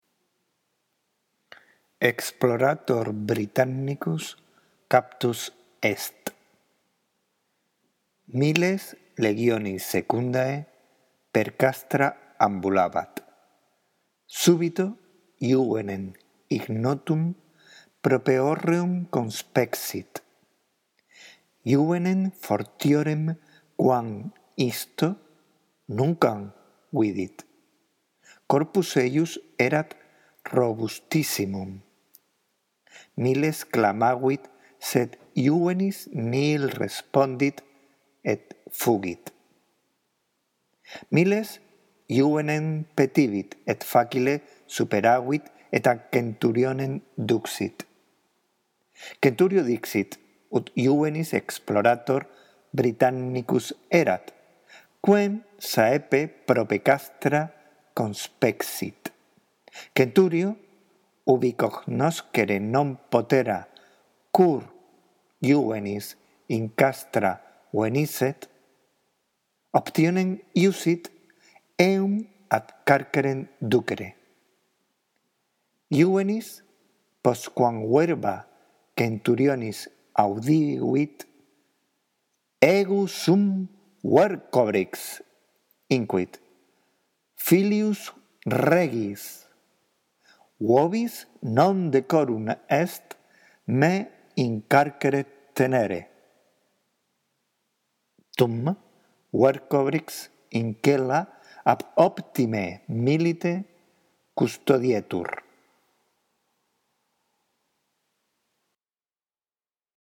La audición de este archivo contribuirá a que mejores tu lectura del latín: